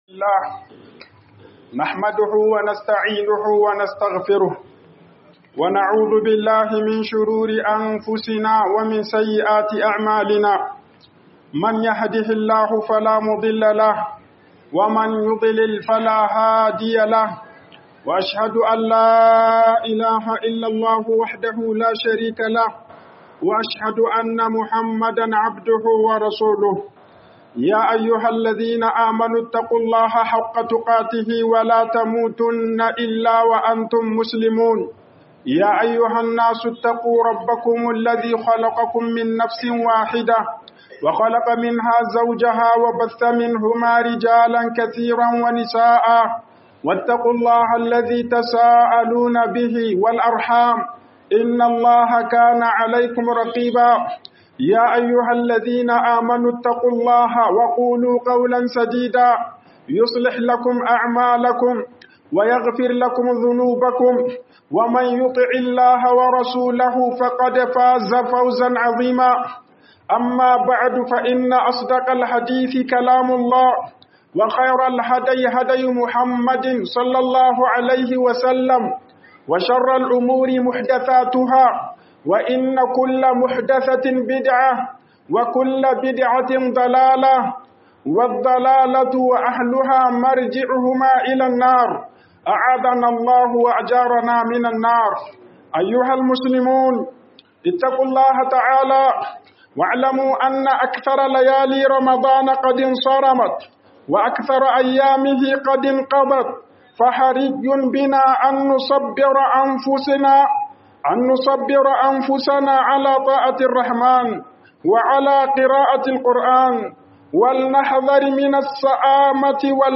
FALALAR GOMAN KARSHE NA RAMADAN - HUƊUBOBIN JUMA'A